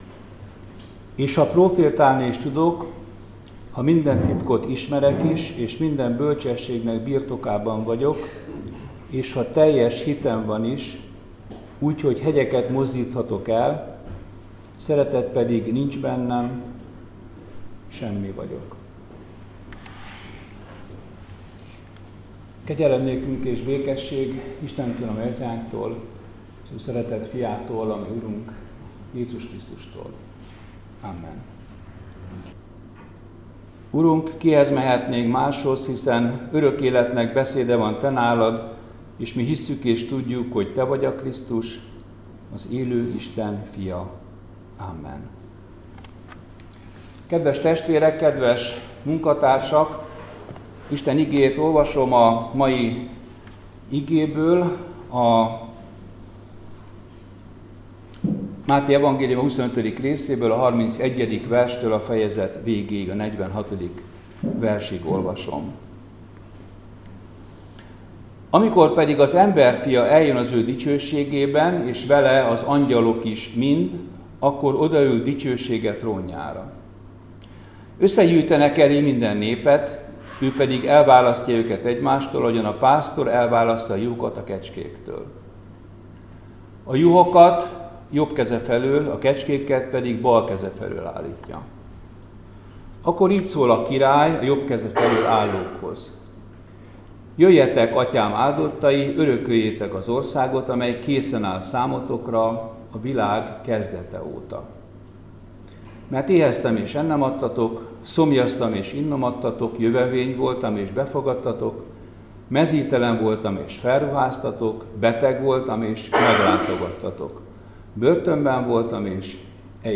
Áhítat, 2024. október 1.